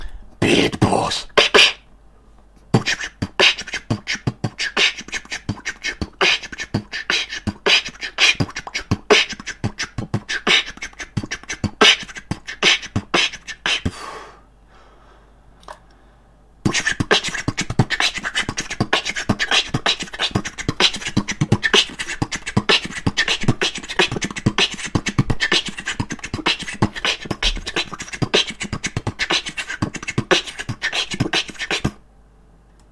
Два простеньких хип-хоп бита
b tbt b | kch tbt b | t b b t | kch tbt t >>> b tbt b | kch tbt b | t kch t b | kch tbt kch
(Вместо киков в tbt и хэтов я какую-то фигню делаю, не очень похожую на свистящий скретч, но вроде ориентироваться можно)
В моём бите я вообще свищу...